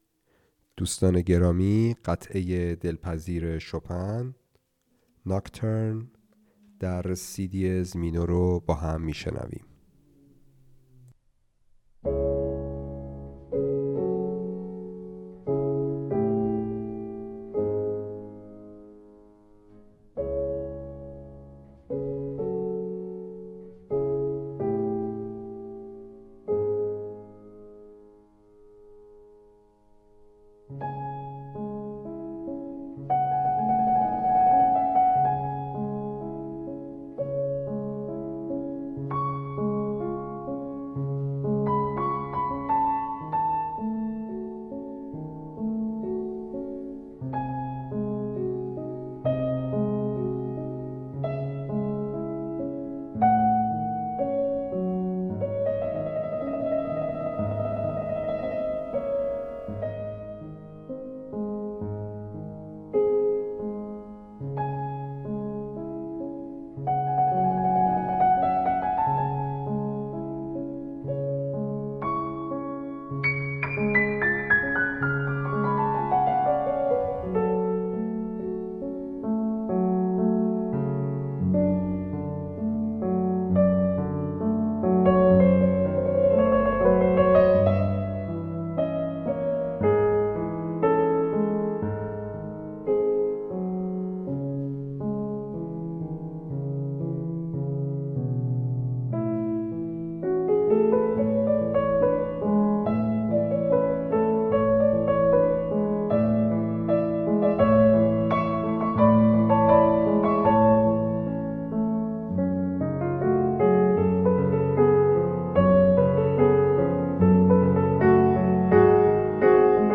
🌙 سبک: کلاسیک، رمانتیک
🎧 حس و حال: آرام، رویایی، عمیق